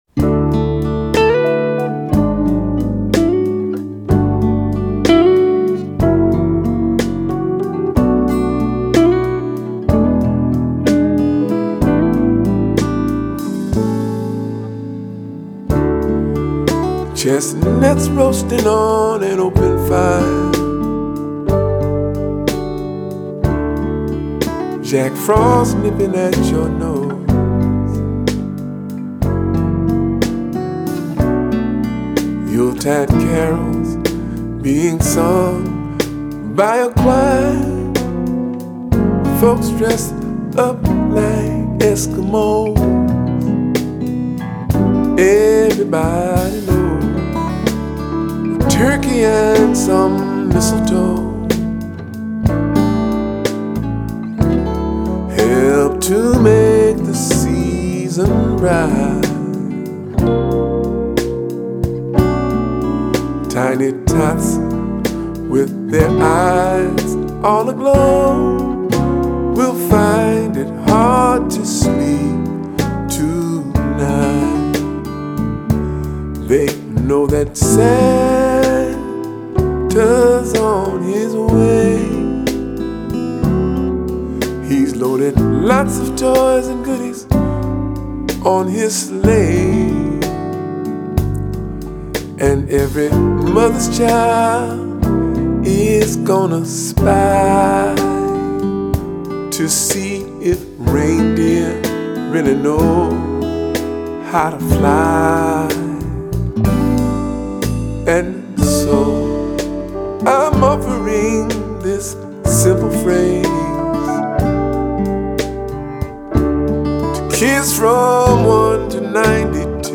Жанр: Contemporary Blues